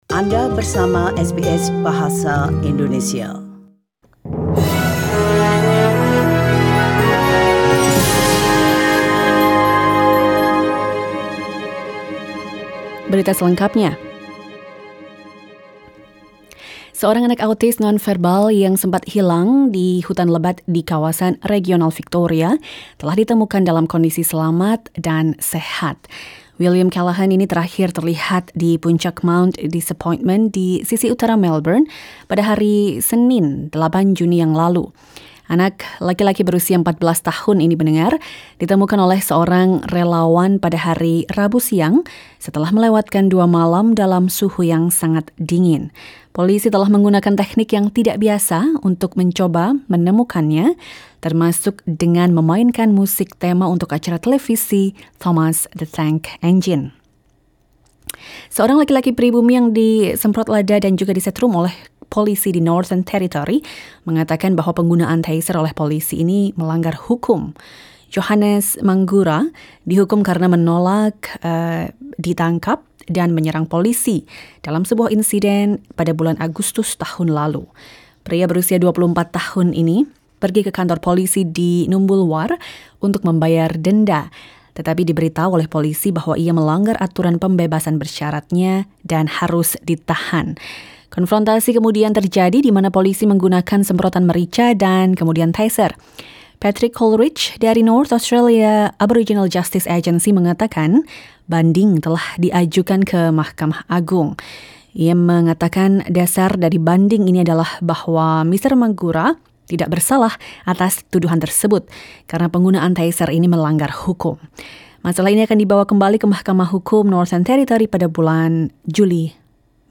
SBS Radio news in Indonesian - 10 June 2020